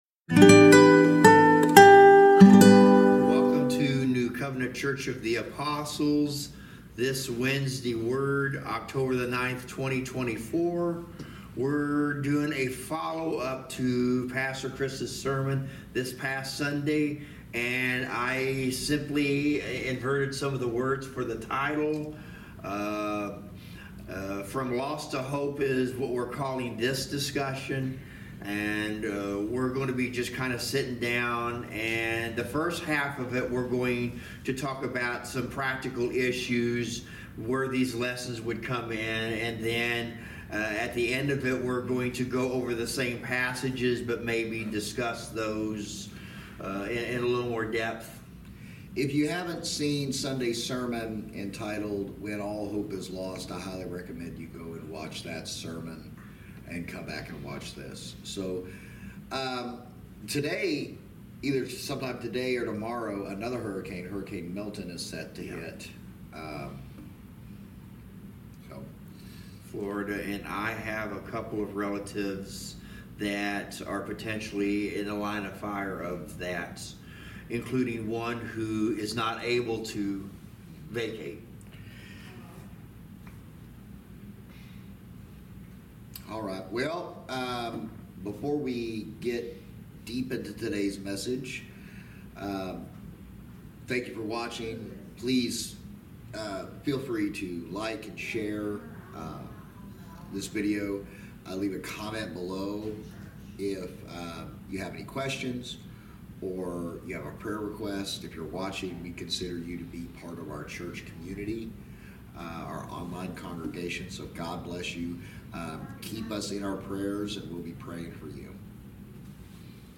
Service Type: Wednesday Word Bible Study